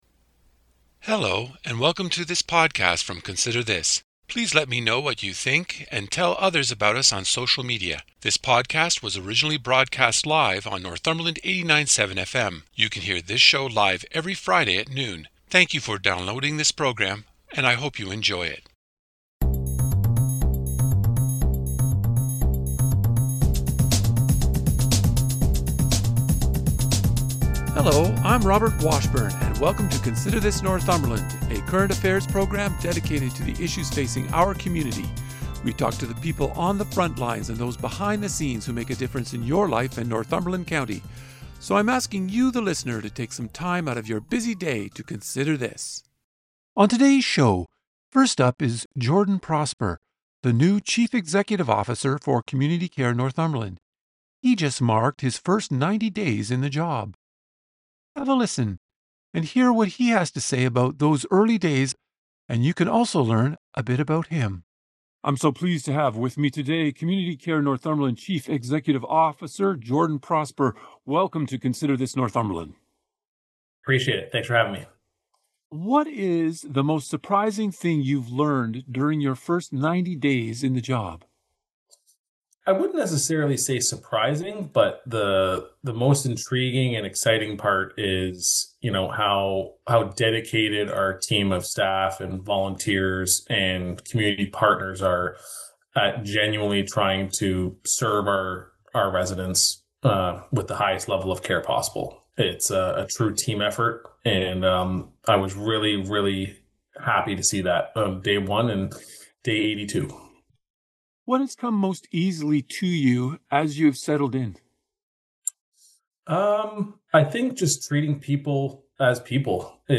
The interview: